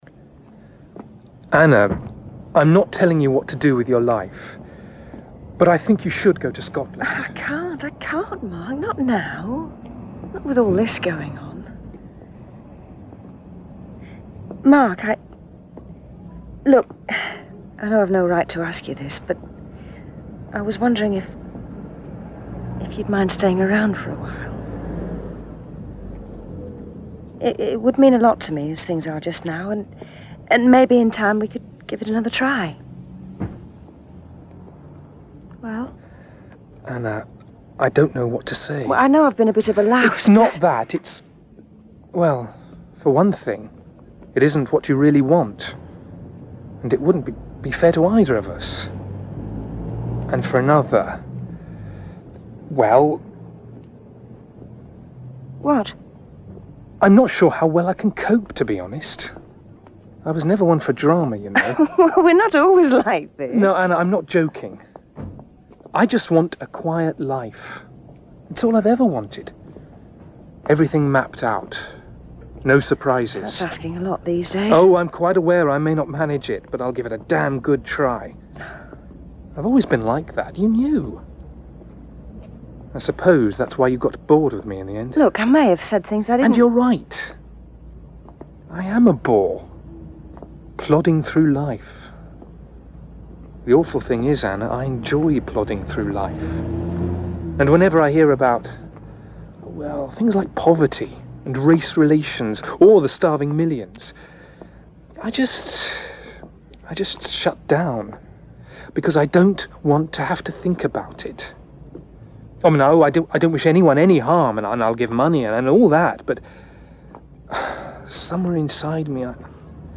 Drama
(Mark)